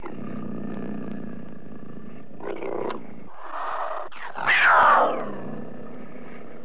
دانلود صدای حیوانات جنگلی 44 از ساعد نیوز با لینک مستقیم و کیفیت بالا
جلوه های صوتی